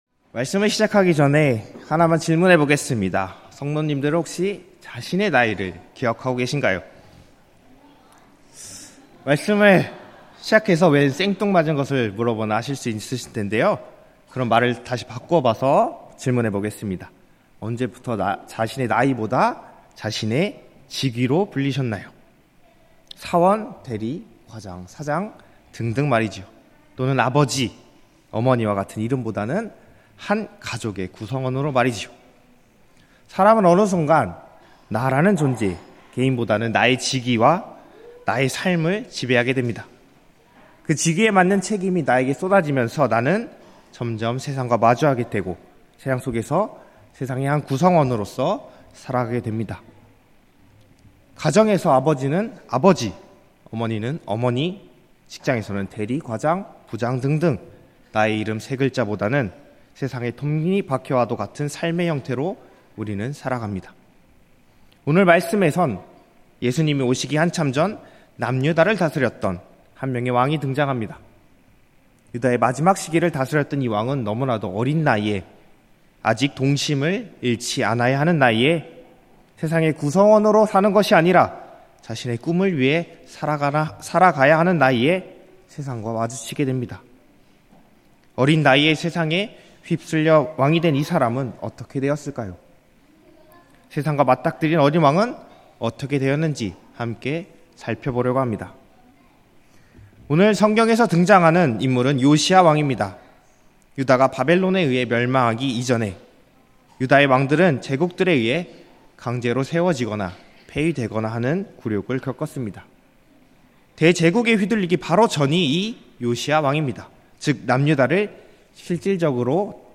2024년 8월 11일 주일오후예배 (아동부서 성경학교 보고예배)
음성설교